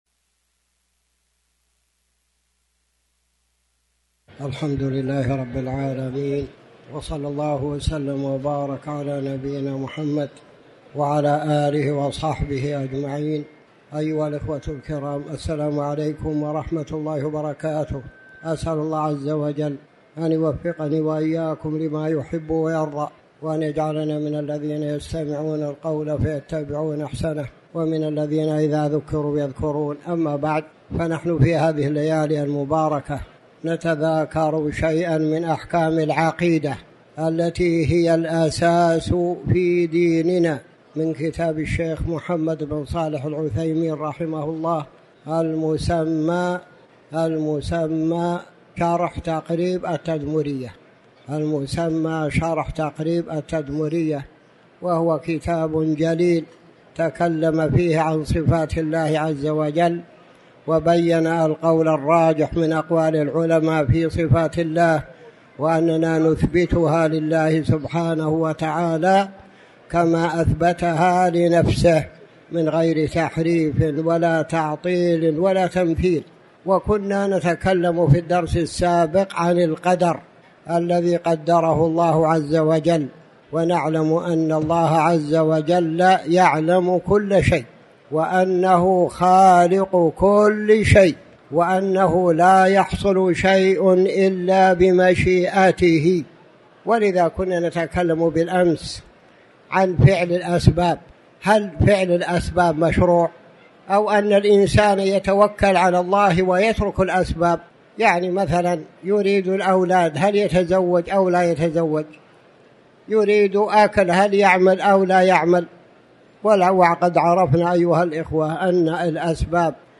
تاريخ النشر ١٧ ربيع الثاني ١٤٤٠ هـ المكان: المسجد الحرام الشيخ